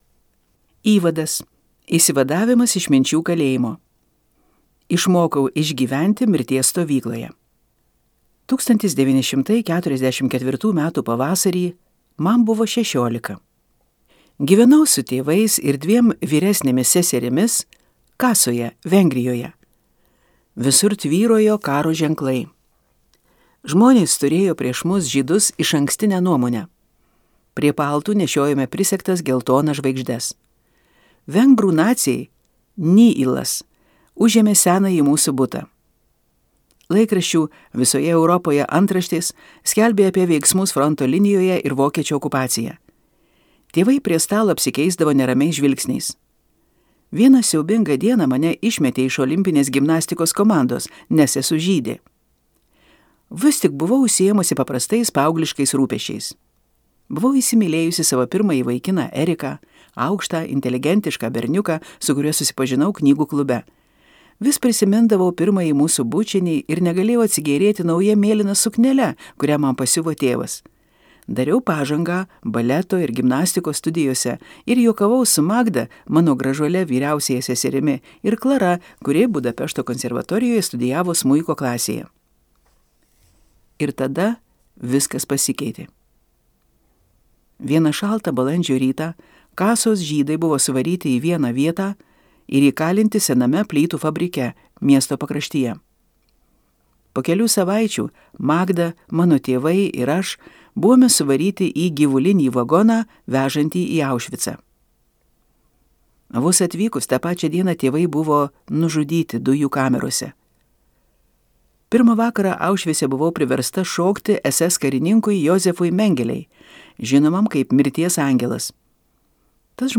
Dovana. 12 gyvenimo pamokų | Audioknygos | baltos lankos